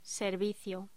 Locución: Servicio